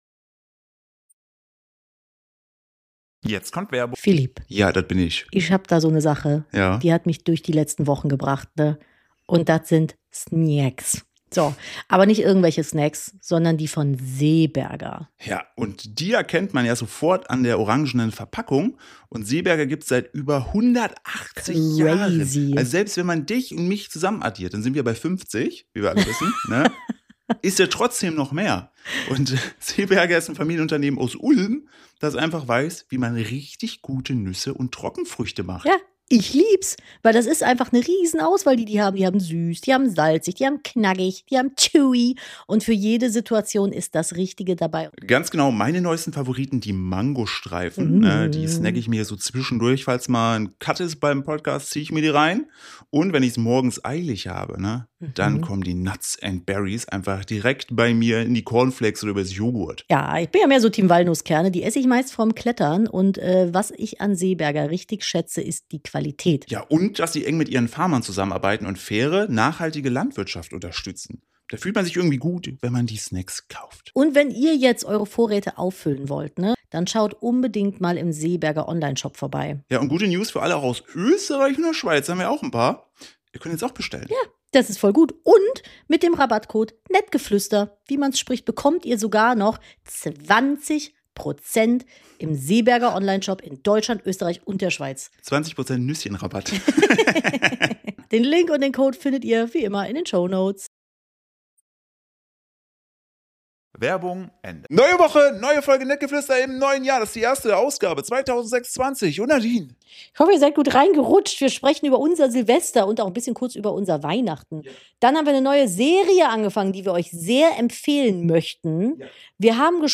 #260: Bare Knuckle Bärbel ~ Nettgeflüster - Der Podcast eines Ehepaars Podcast